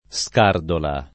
scardola [ S k # rdola ]